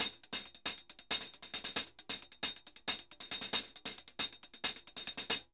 HH_Merengue 136-1.wav